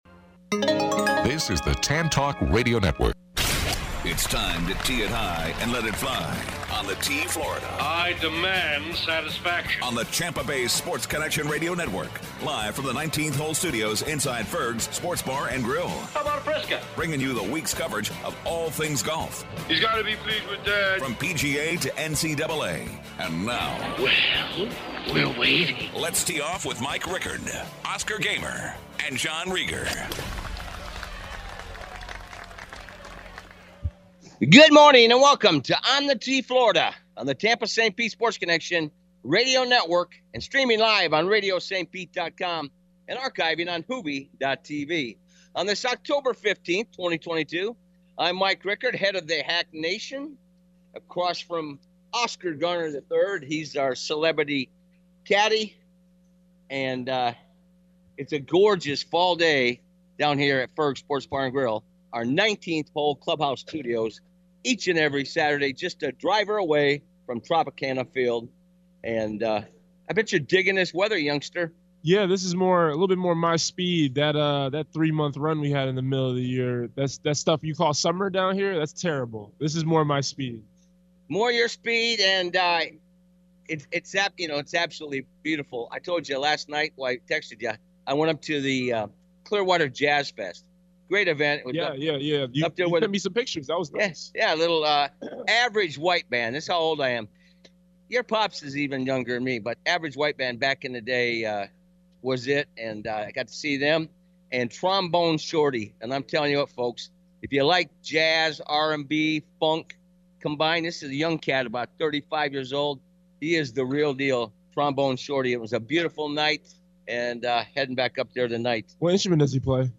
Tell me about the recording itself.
"On The Tee Florida" 10-15-22 Live from Ferg's w